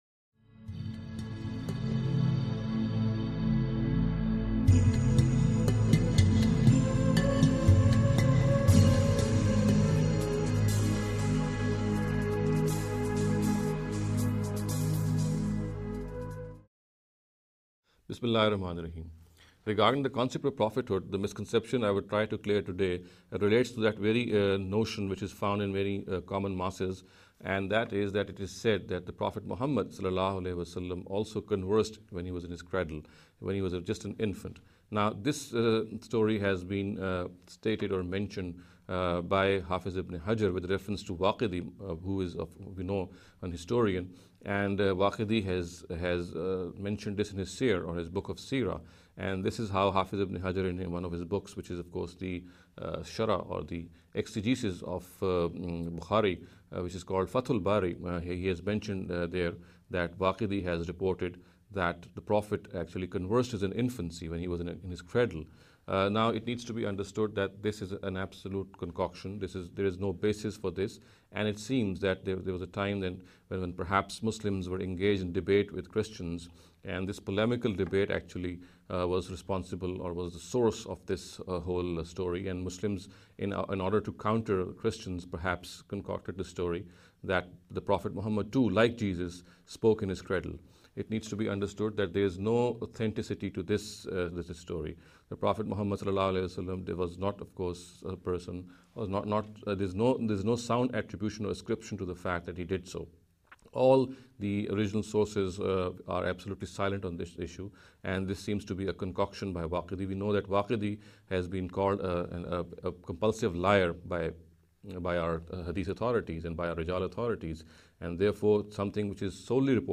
This lecture series will deal with some misconception regarding the Concept of Prophethood. In every lecture he will be dealing with a question in a short and very concise manner. This sitting is an attempt to deal with the question 'Did Muhammad (sws) Speaks in the Cradle?’.